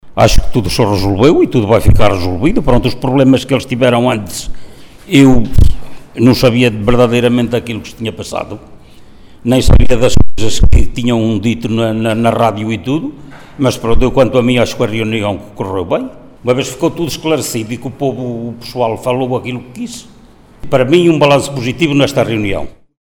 Cassiano Rosa, Presidente da Mesa da Assembleia de Freguesia. em declarações à Alive FM, fez um balanço positivo da reunião da Assembleia de Freguesia referente a Junho de 2025.
Cassiano-Rosa-Pres.-Mesa-da-Assembleia-de-Freguesia.mp3